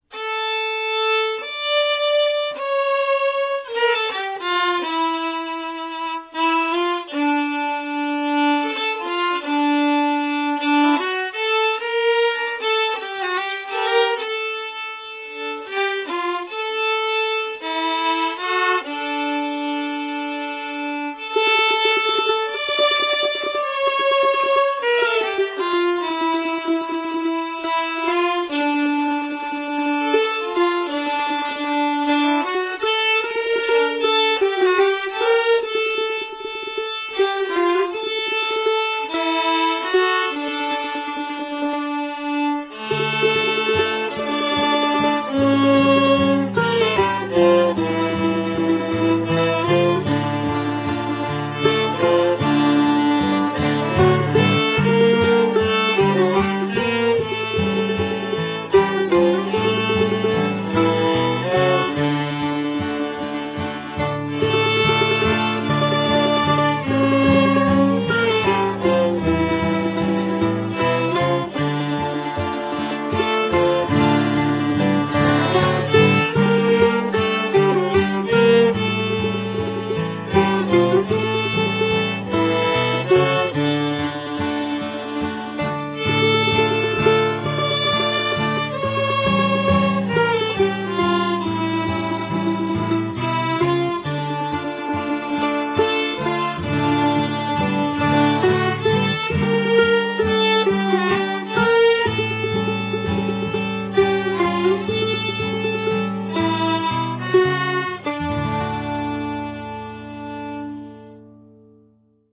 fiddles
mandolin
guitar
drums